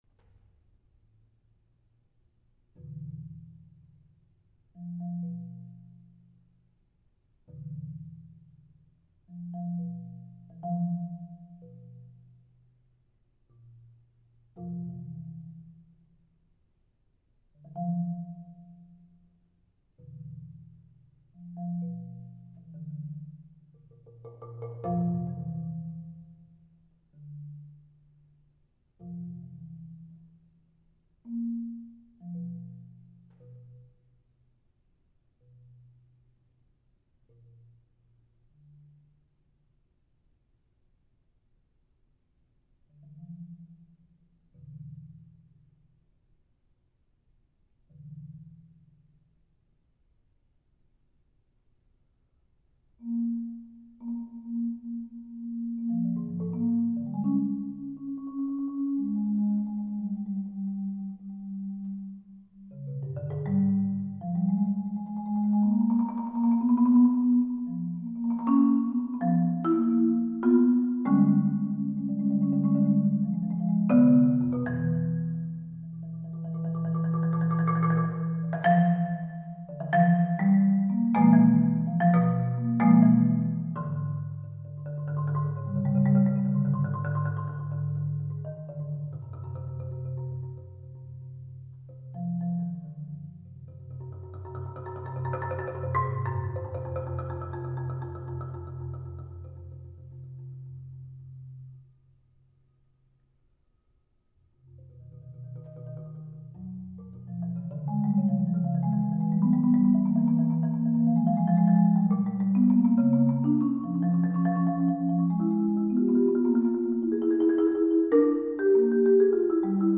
for solo marimba